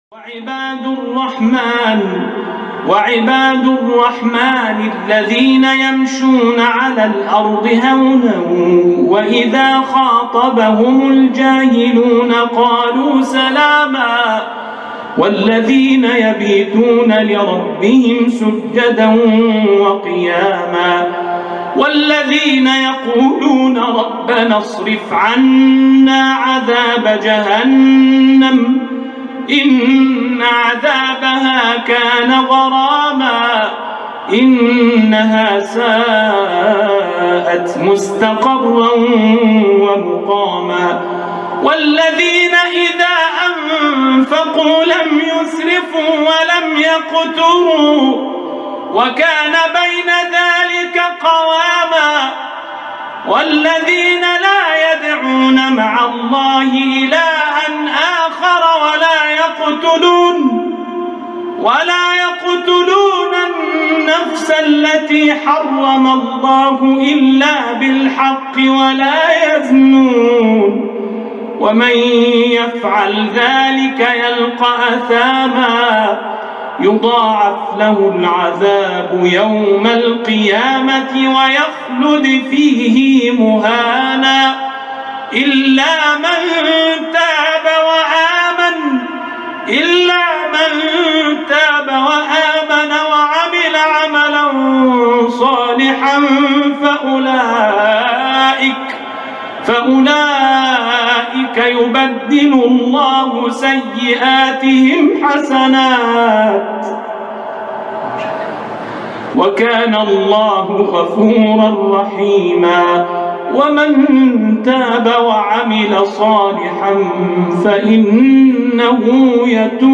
Impressive and distinctive Rare and special Recitations